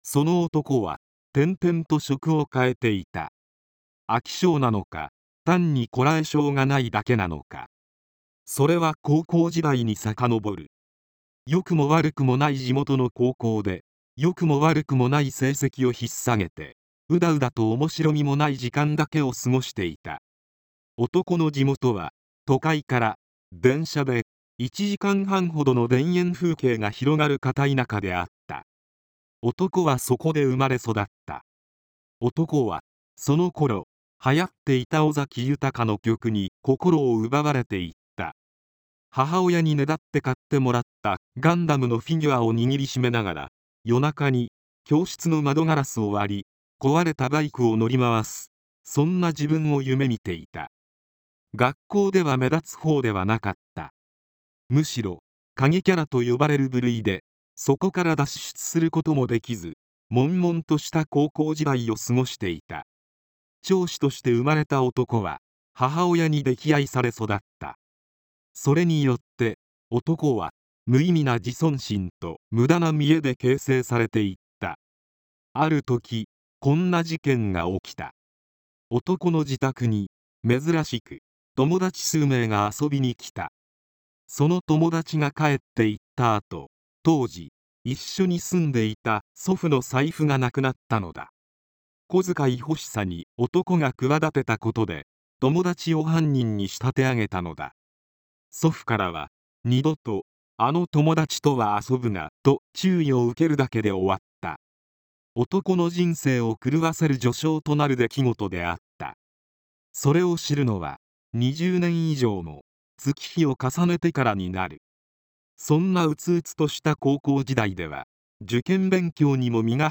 今回は、そんな音声合成の実験として、ふたつばかりのテキストを読み上げさせてみました。
悪くはないのですが、しょせんは音声合成です。
とはいえ、読み上げの速さ、間の取り方は今回の音声合成の感じで悪くないと思います。